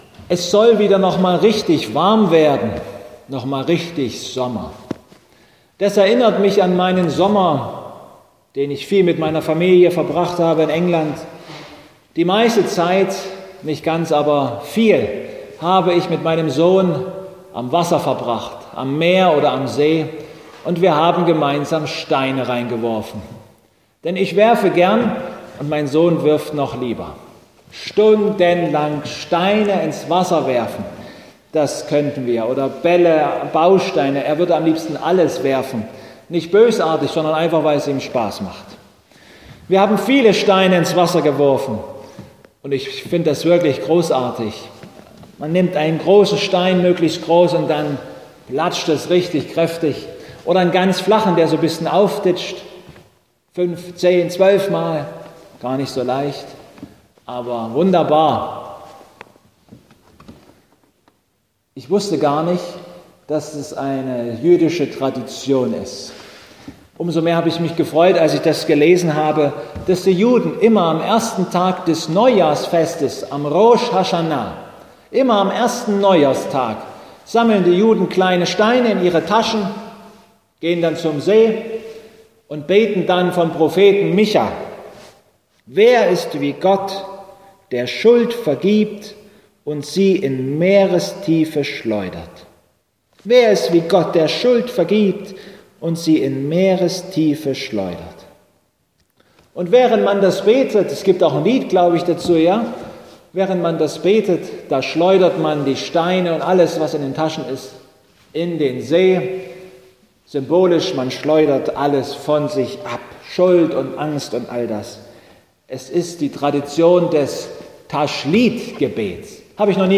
1.Petrus 5,7 Gottesdienstart: Abendmahlsgottesdienst http